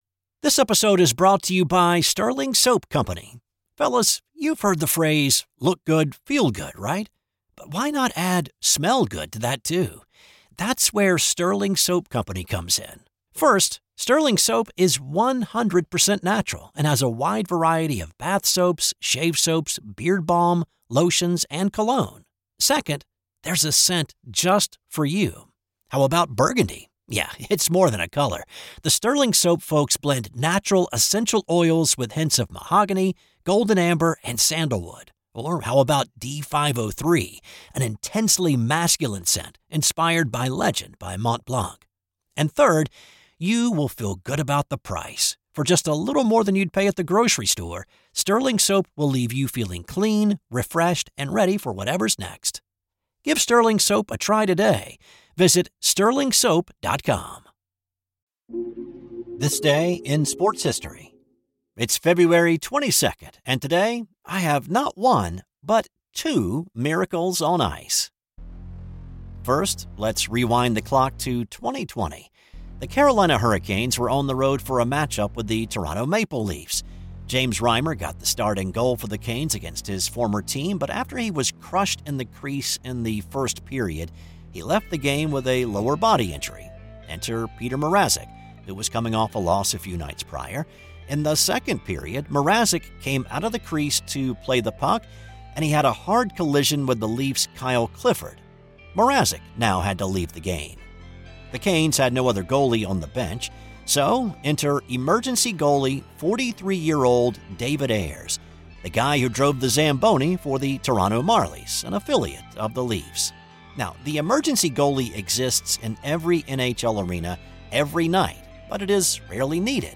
I research, write, voice, and produce each show.